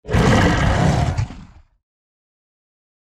دانلود آهنگ گرگ 2 از افکت صوتی انسان و موجودات زنده
جلوه های صوتی
دانلود صدای گرگ 2 از ساعد نیوز با لینک مستقیم و کیفیت بالا